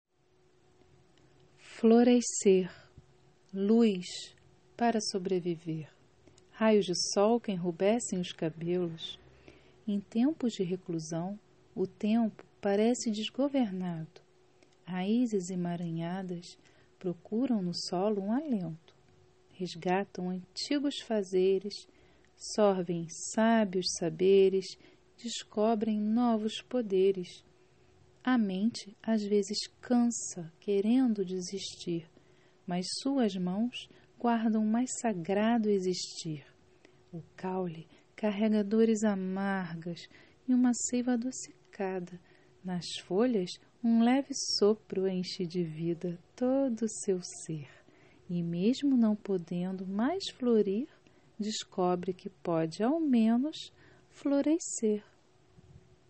Poesia com voz humana